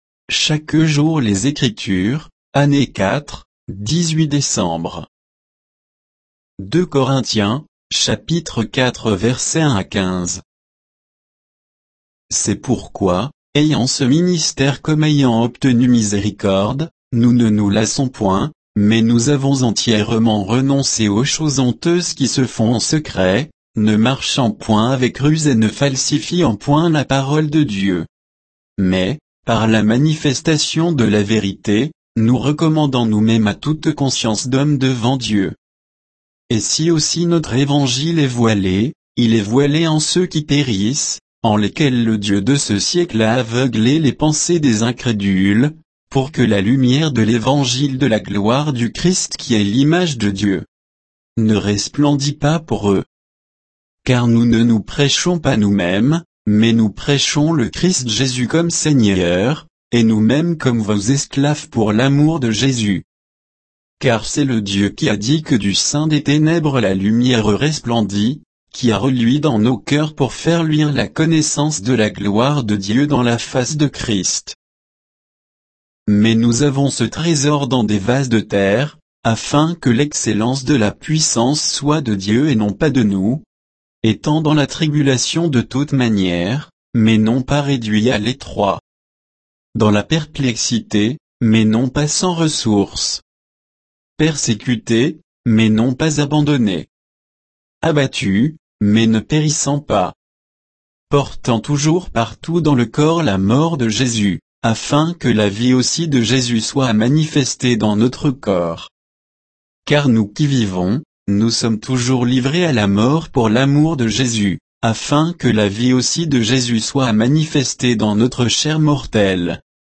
Méditation quoditienne de Chaque jour les Écritures sur 2 Corinthiens 4, 1 à 15